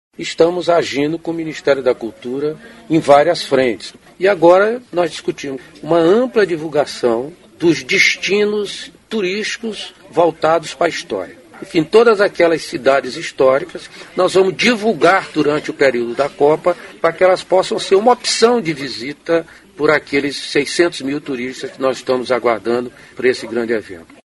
aqui e ouça declaração do ministro Gastão Vieira sobre parceria com o Ministério da Cultura para a divulgação de destinos históricos no país.